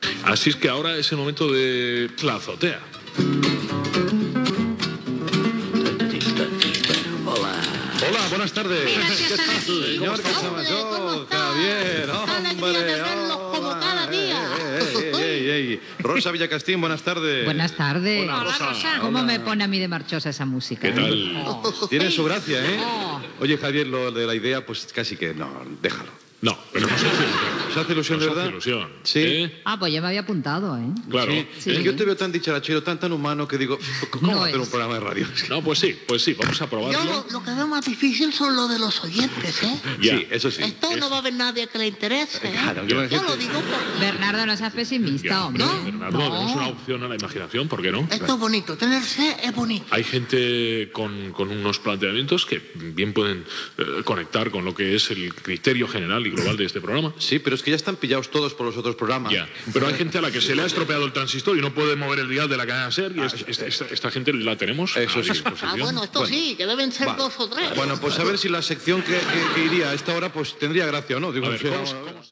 Secció "La azotea", feta pels integrants de El Terrat de Ràdio Barcelona 2
Entreteniment